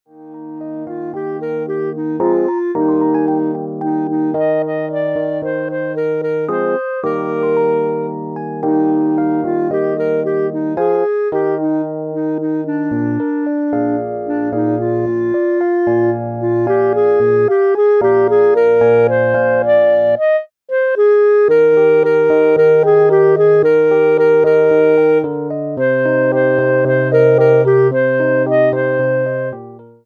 for Clarinet & Keyboard
A super solo for the Festive Season.